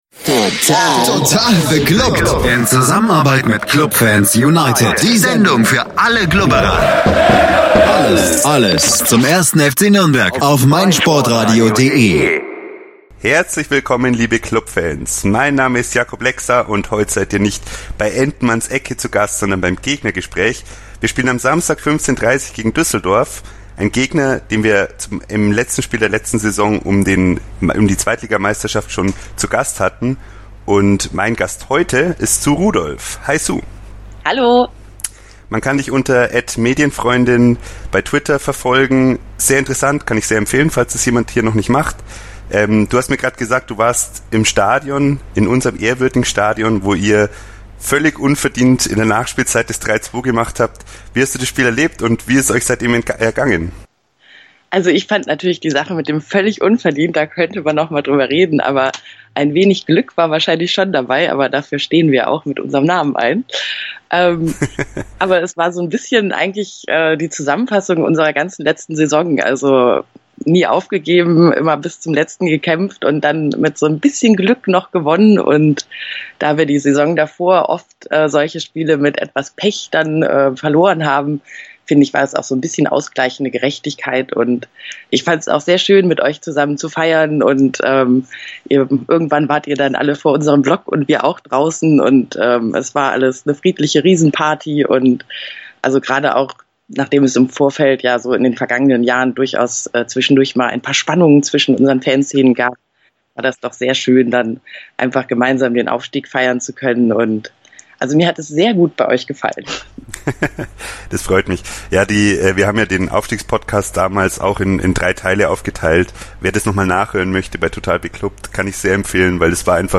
Anmerkung: Die Aufnahme fand vor den Mittwochspielen statt und liefert damit keine Eindrücke des BVB-Spiels.